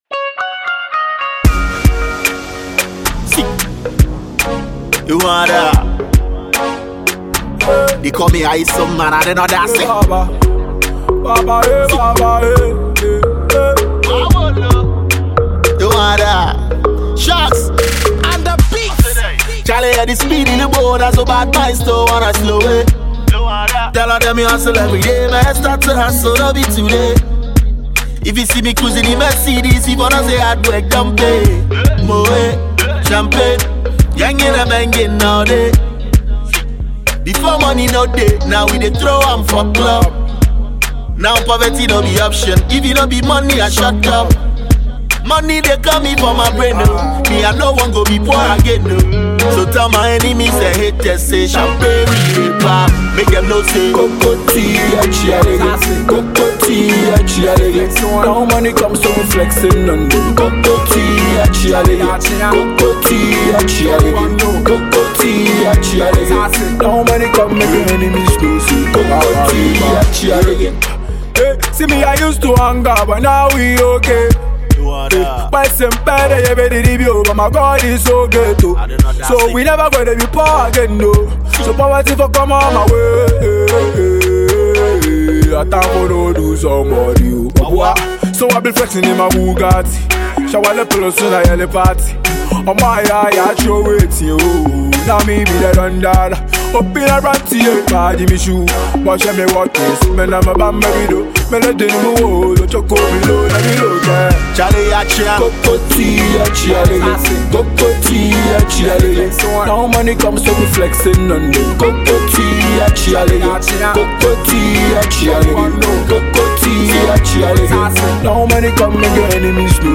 Ghana MusicMusic
High Life